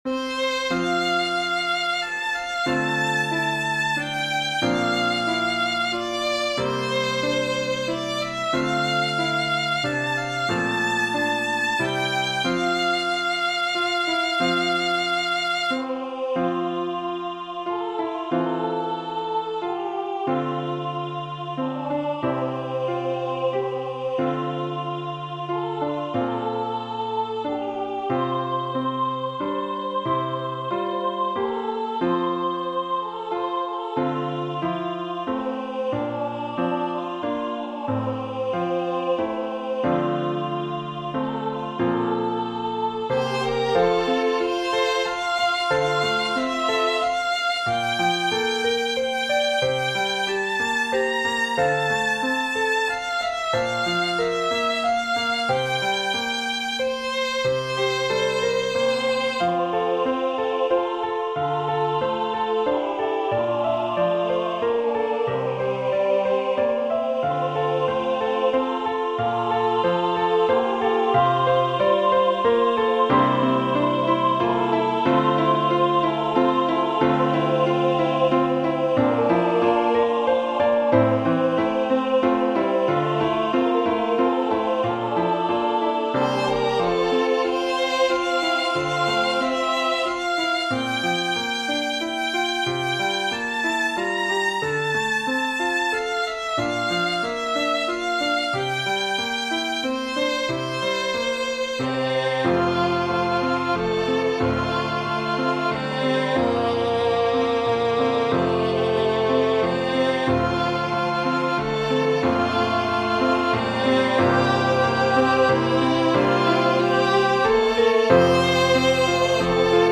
Amazing Grace Hymn #1010 SATB with Piano and Violin Accompaniment. It is a hymplicity style arrangement where the choir sings parts straight out of the hymnbook.